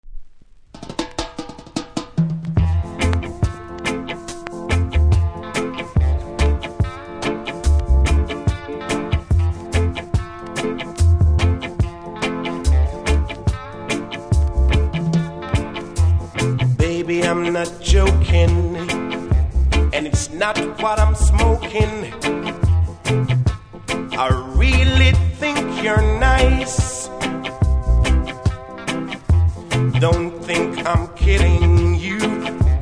REGGAE 70'S